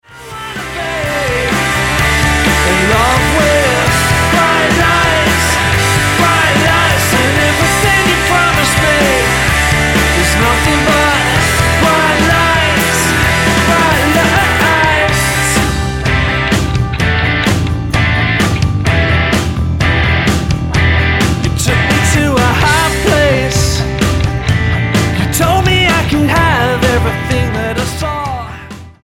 rock trio
Style: Pop